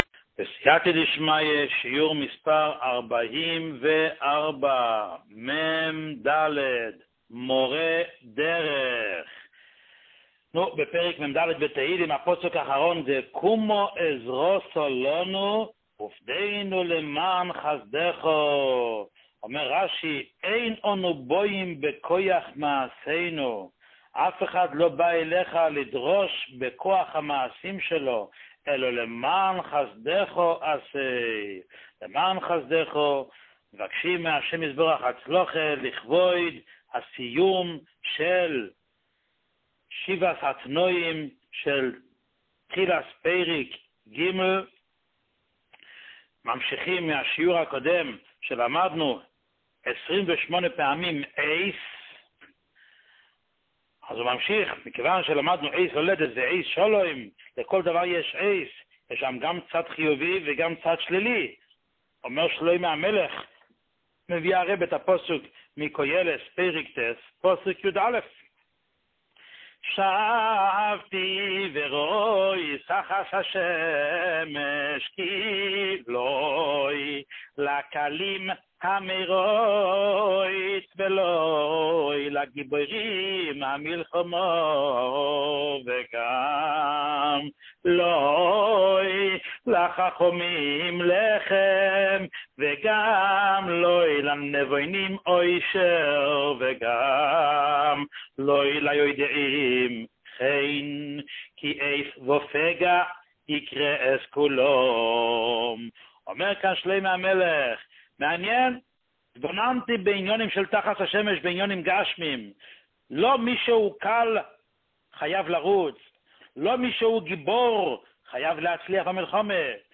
שיעור 44